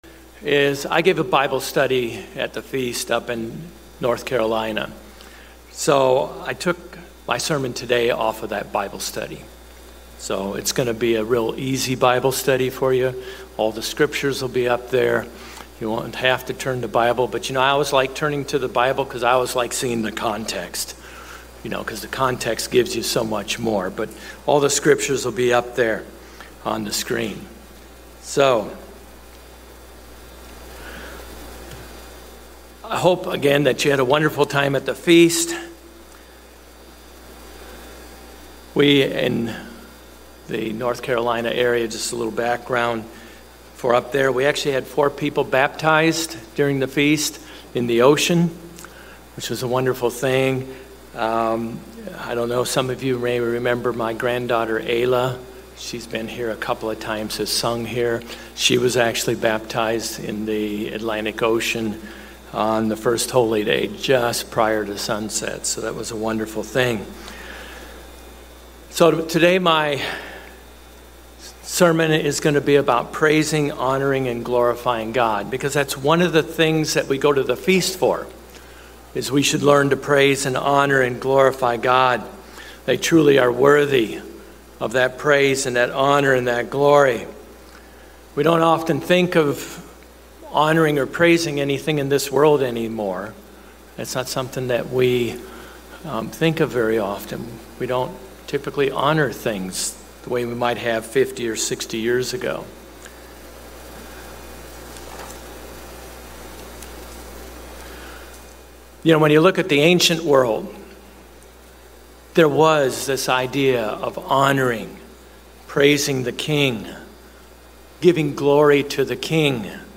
We read often in the Bible of various people praising, honoring and glorifying God. This PowerPoint sermon discusses several reasons why we are to praise, honor and glorify God along with ways that we can praise, honor and glorify God.